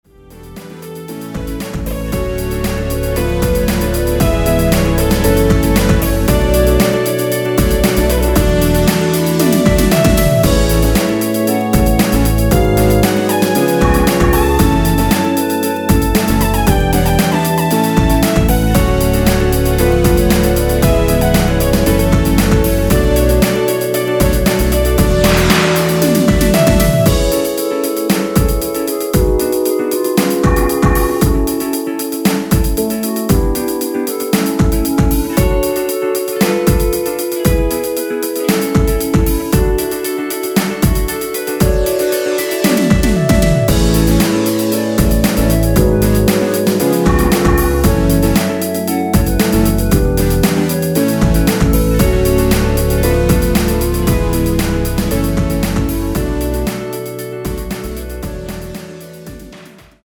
원키에서(-2)내린 2절 삭제한 멜로디 포함된 MR 입니다.(본문의 가사및 미리듣기 확인)
음정과 박자 맞추기가 쉬워서 노래방 처럼 노래 부분에 가이드 멜로디가 포함된걸
앞부분30초, 뒷부분30초씩 편집해서 올려 드리고 있습니다.
중간에 음이 끈어지고 다시 나오는 이유는